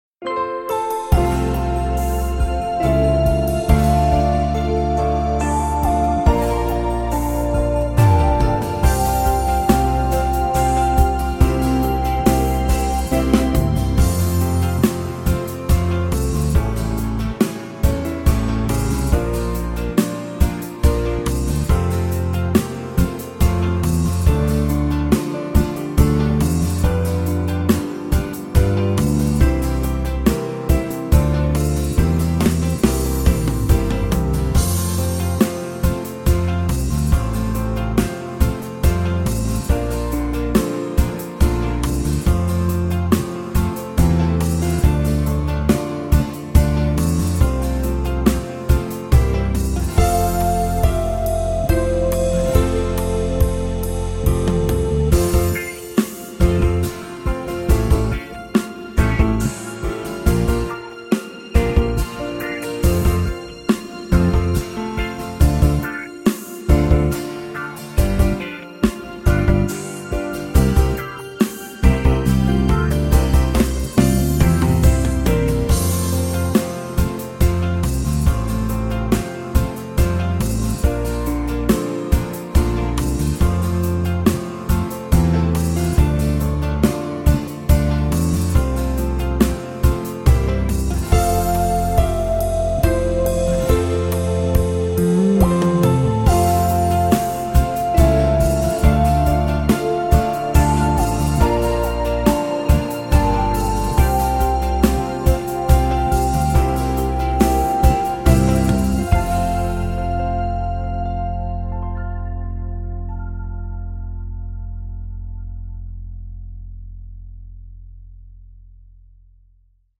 Besetzung: Blasorchester
5 Songs - 12 Tonarten - 180 Duettvariationen - 60 Playbacks.